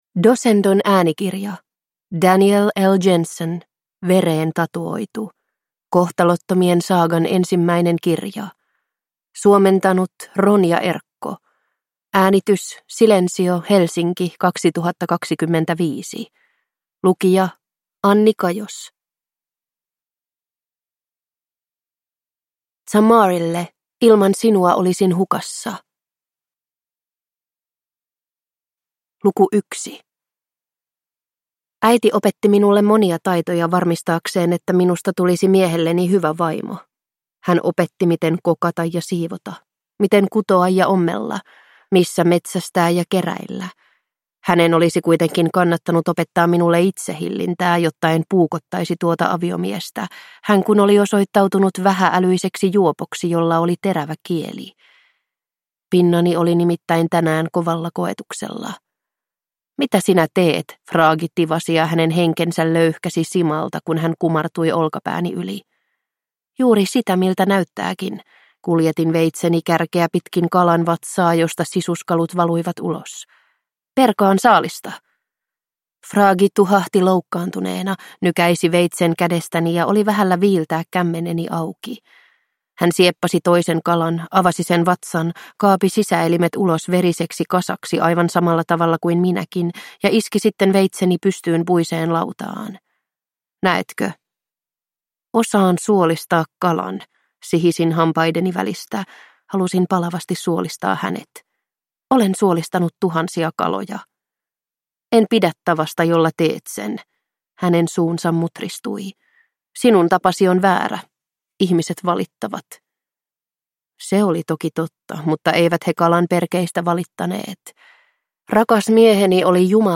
Vereen tatuoitu – Ljudbok